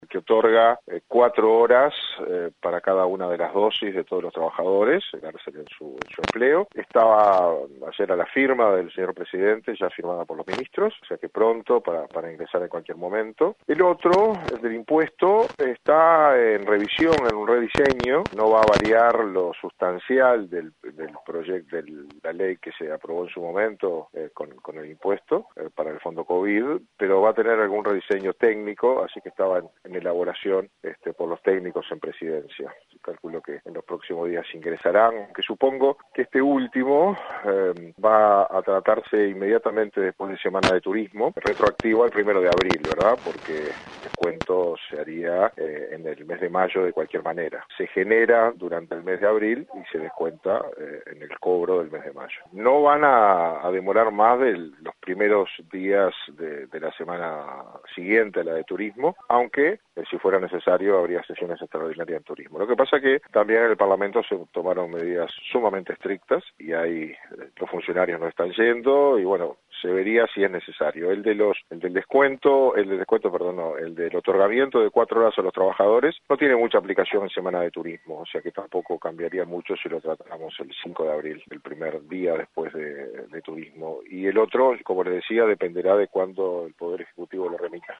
Luego de Semana de Turismo, el Parlamento votará los proyectos de ley que otorgarían hasta cuatro horas libres para que los trabajadores se trasladen a vacunar y la aplicación del tributo Covid—19 a los funcionarios públicos con sueldos altos durante los dos meses. De todos modos, el senador nacionalista, Jorge Gandini dijo a 970 Noticias, que no se descarta que pueda haber sesiones extraordinarias en Turismo.